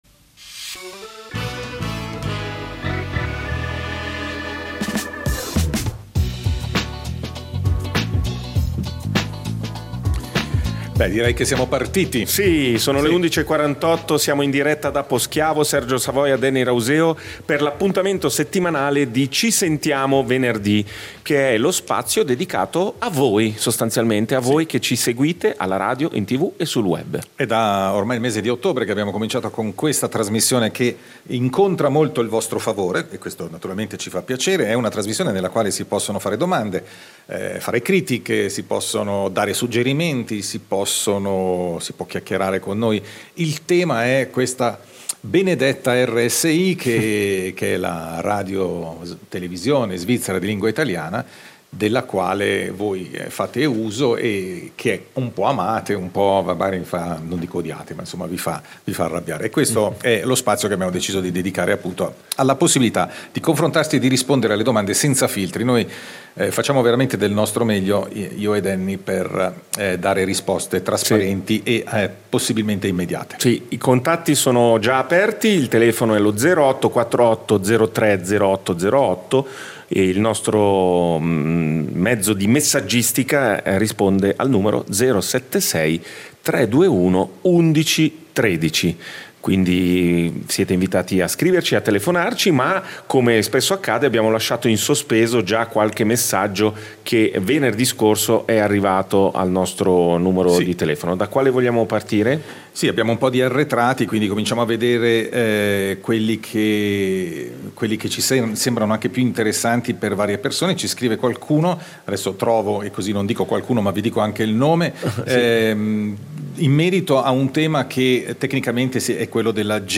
In diretta da Poschiavo